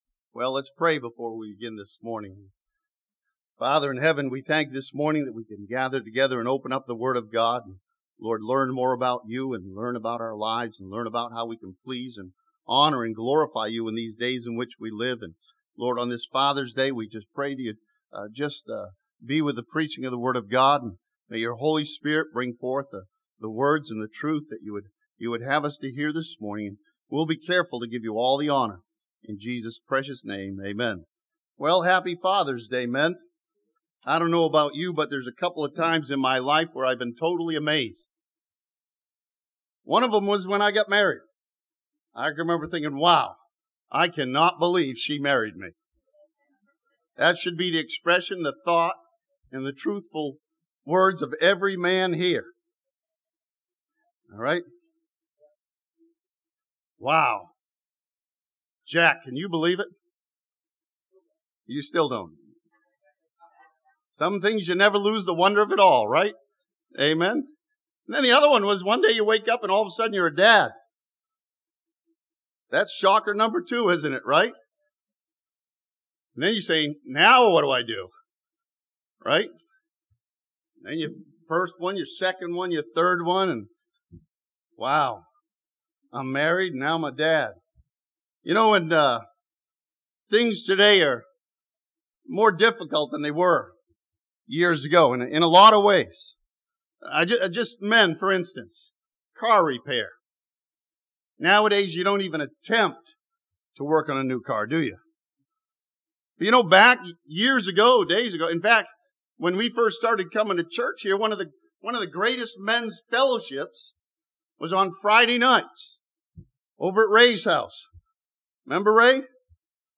This sermon from Psalm 128 examines the steps for being a successful father according to the Word of God.